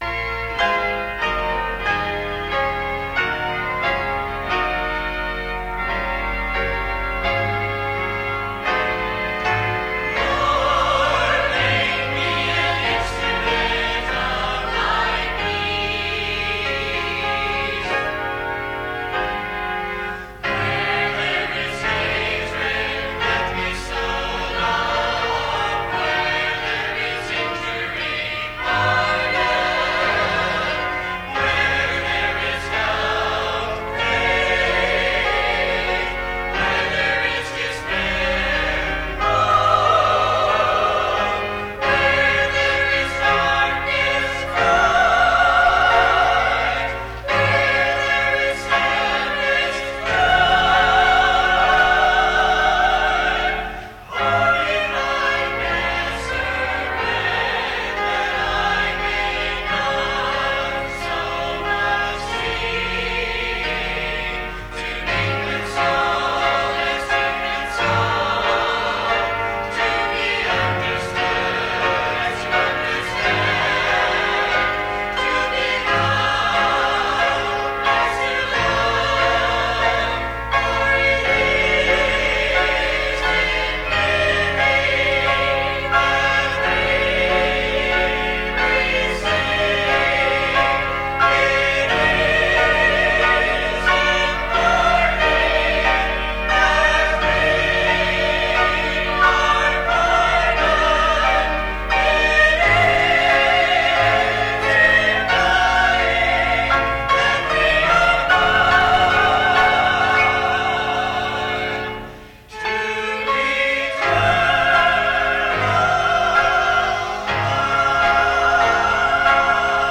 Choir - New Leaves
Here are selections from the choir, in case the title wasn't obvious enough!
This choir page contains only 15 selections that represent many of the years when the church had a choir.